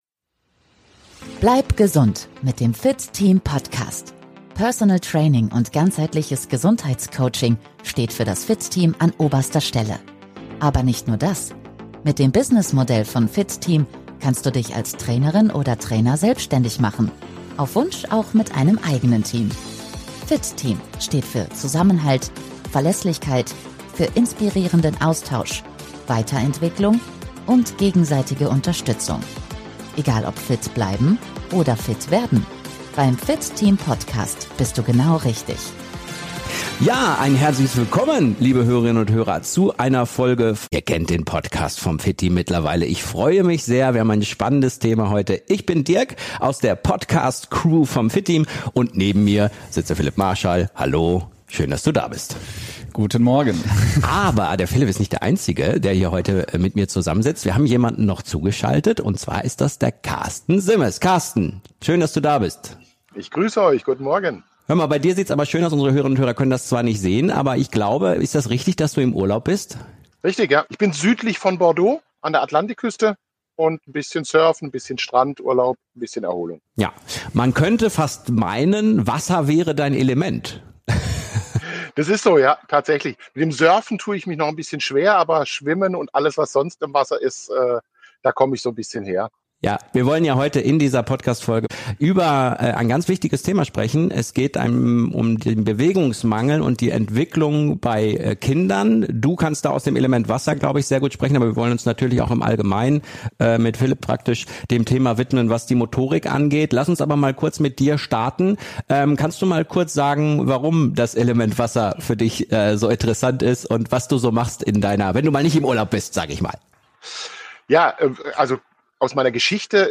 Ein wichtiges Gespräch über Bewegung, Ängste, Erziehung – und wie wir Kindern wieder mehr Vertrauen in ihren Körper geben.